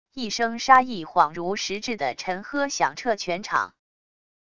一声杀意恍如实质的沉喝响彻全场wav音频